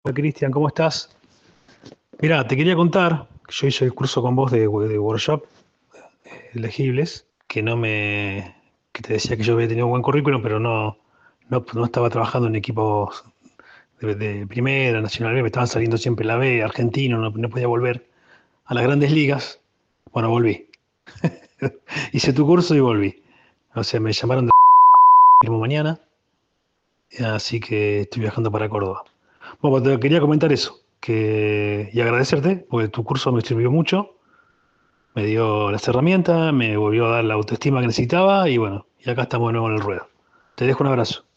TESTIMONIOS DE ALGUNOS DE LOS PARTICIPANTES
Escucha el testimonio de un participante de esta capacitación y los resultados que logró aplicando el contenido